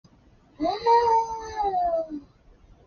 Play, download and share plague loading up a puke original sound button!!!!